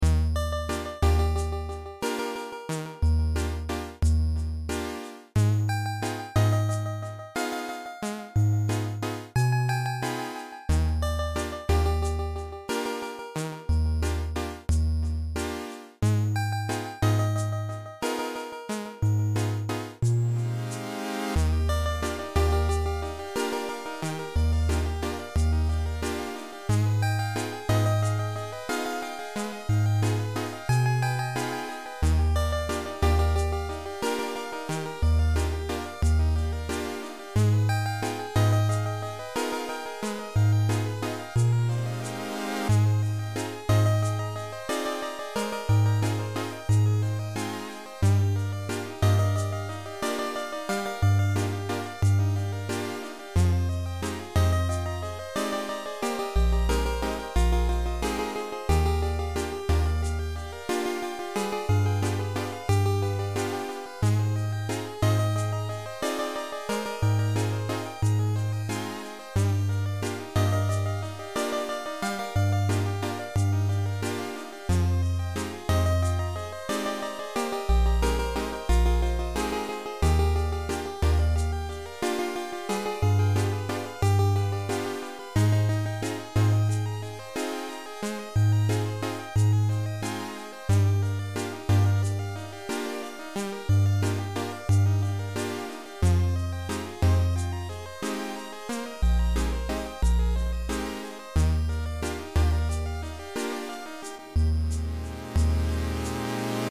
i'm glad you asked! it's an online sequencer/DAW thing where you can make simple loops or full songs. it's completely free and online you can find it here. i promise im not sponsored or anything i just really like this website since i'm too lazy to actually learn a music program like FL studio LOL. actually, beepbox is the original website, but i use jummbox cause i think it has a lot more features than beepbox. there are like a million different beepbox variations out there (because beepbox is open source) and theres a discord too if you want to check all of them out